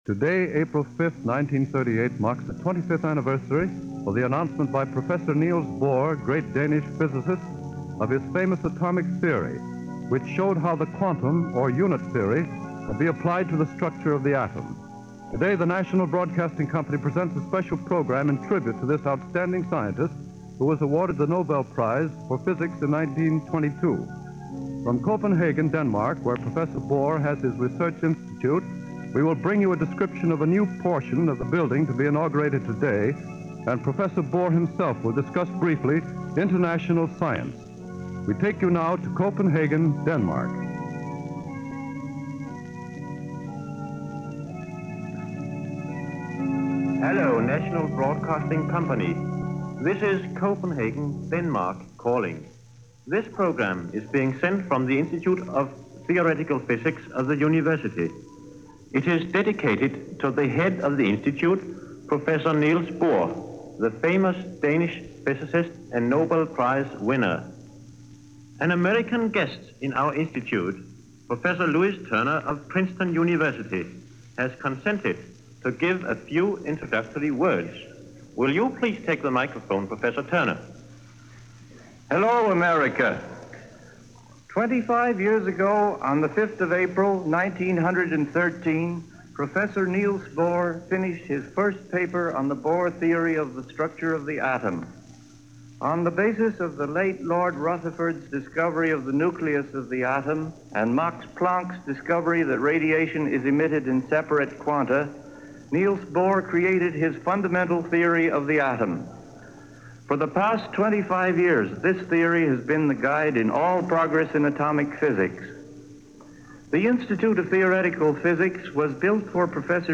A Word Or Two From Neils Bohr - April 5, 1938 - An address by Neils Bohr on the occasion of the anniversary of his discover of the Quantum Theory.
April 5, 1938 – Special Broadcast From Copenhagen – Neils Bohr Address-